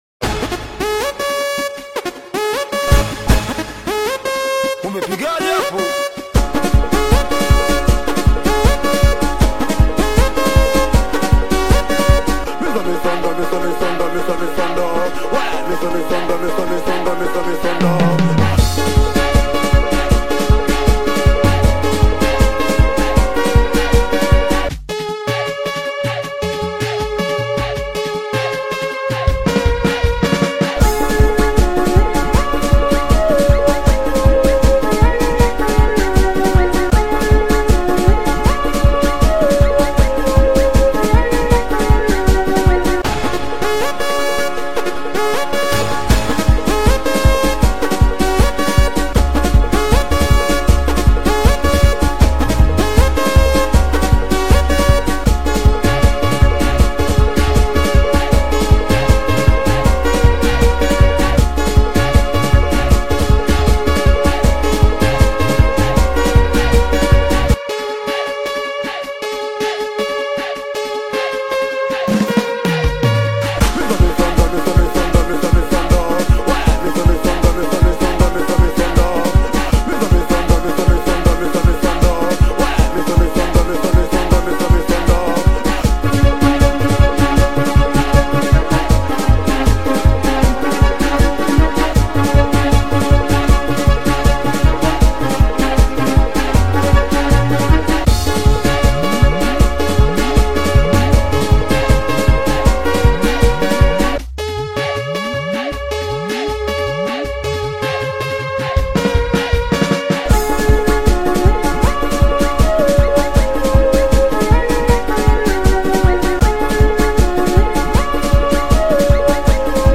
Latest Tanzania Afro-Beats Single (2026)
Genre: Afro-Beats